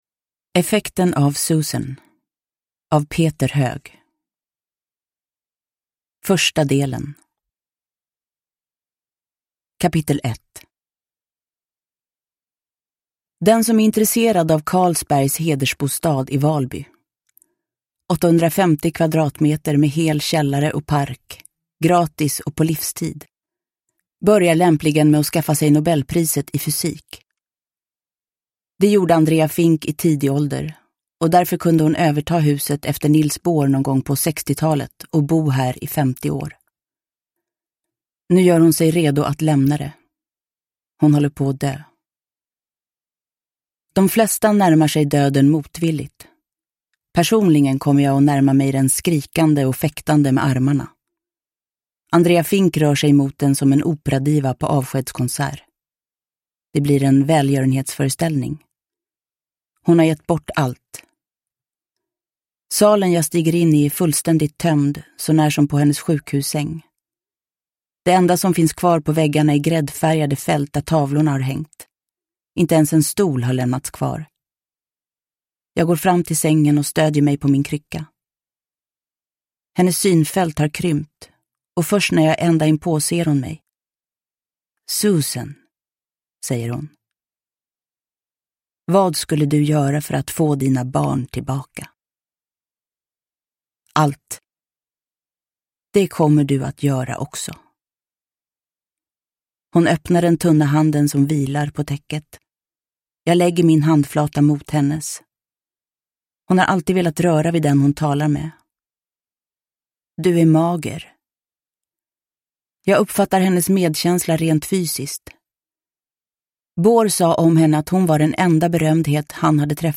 Effekten av Susan – Ljudbok – Laddas ner
Uppläsare: Jessica Liedberg